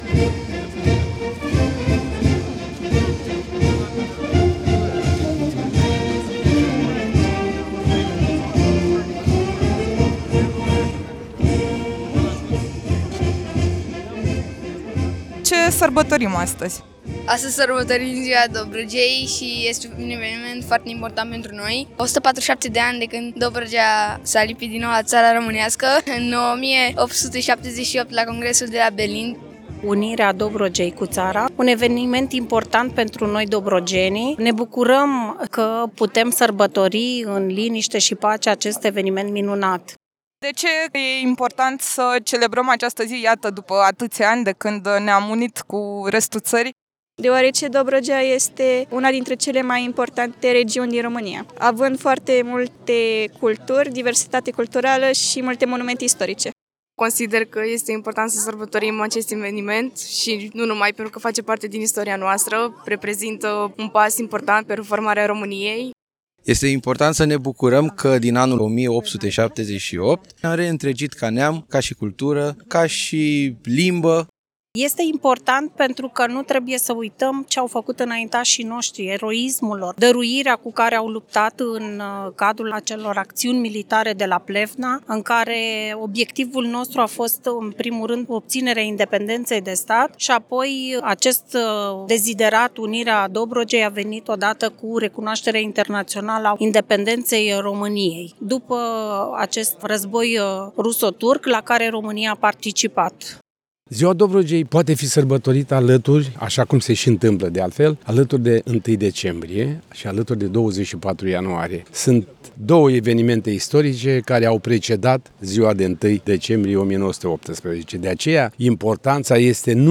Astăzi, de Ziua Dobrogei, la Monumentul Independenței din Tulcea a avut loc ceremonia de depunere a coroanelor, un moment de recunoaștere a celor 147 de ani de la revenirea Dobrogei la România.